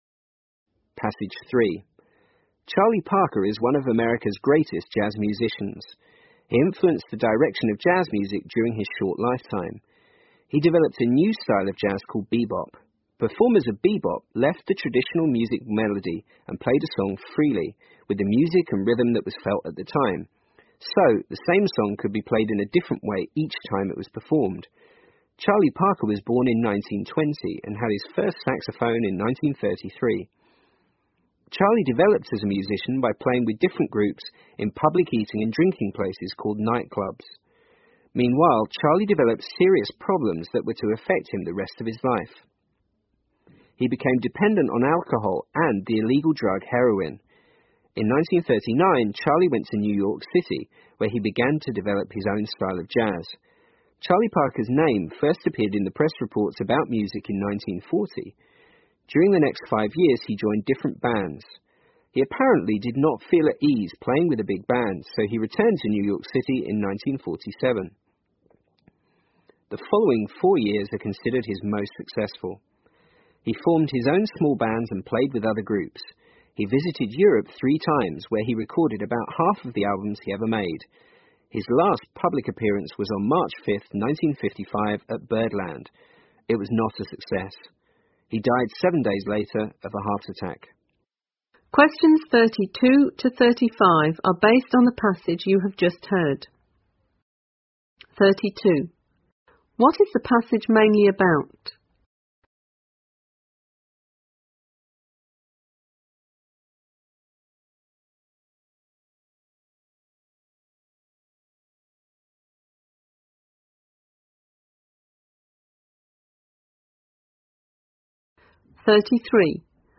在线英语听力室222的听力文件下载,英语四级听力-短对话-在线英语听力室